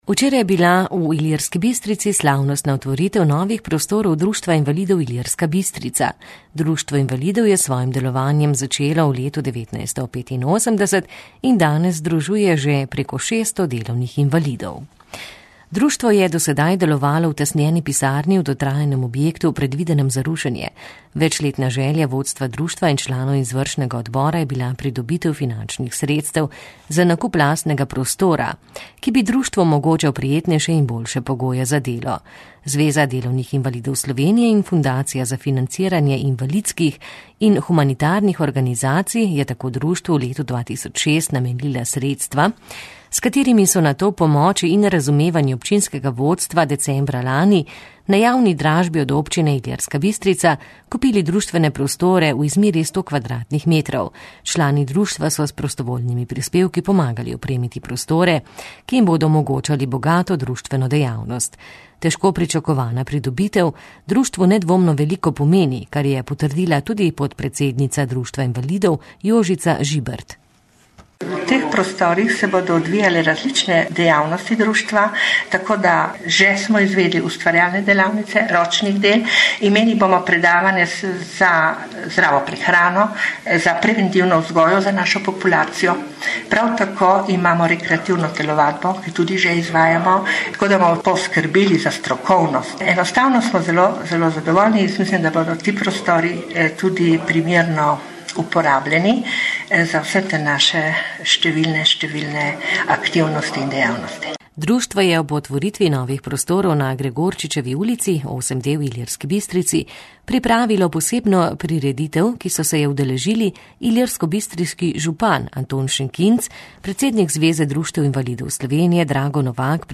Bogato društveno dejavnost in večjo možnost za raznovrstno pomoč svojim članom je Društvo invalidov Ilirska Bistrica dobilo z novimi prostori. Poslušaj prispevek, posnet na slavnostnem odprtju.